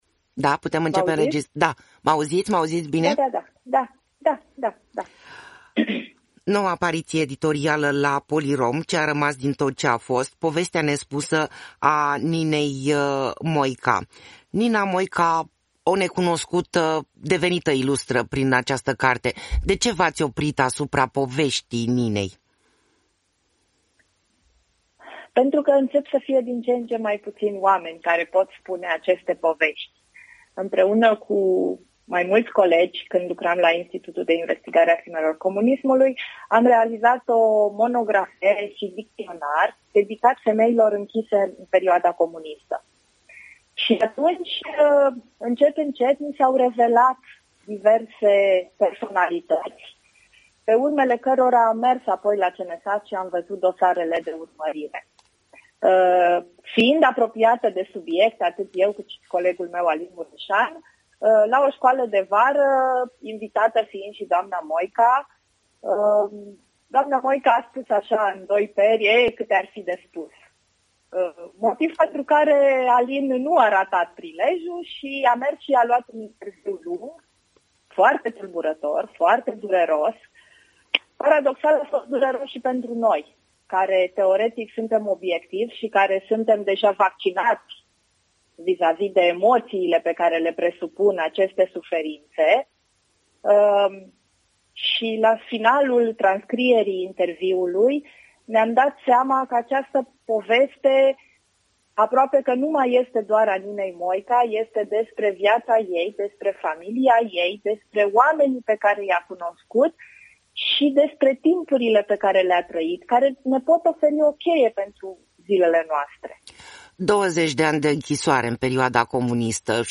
INTERVIU.